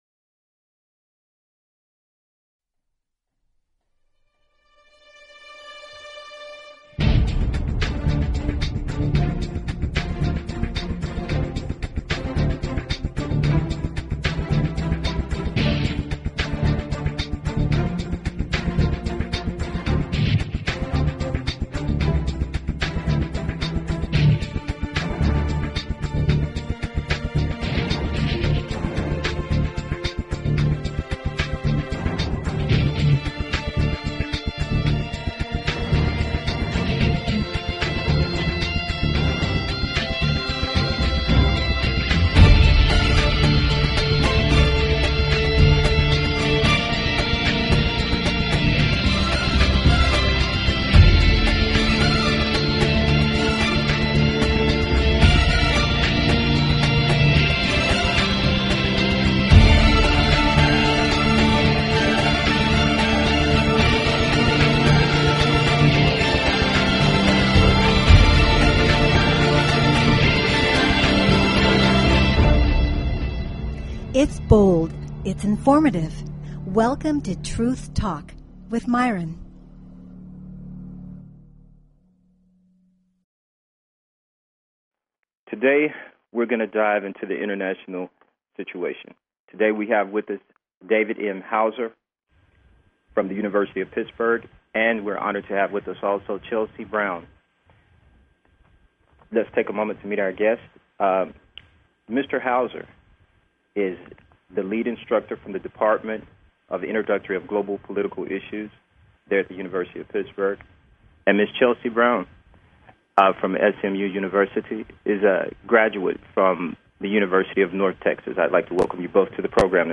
Talk Show Episode, Audio Podcast, Truth_Talk and Courtesy of BBS Radio on , show guests , about , categorized as